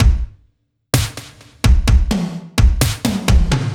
Index of /musicradar/french-house-chillout-samples/128bpm/Beats
FHC_BeatB_128-01_KikSnrTom.wav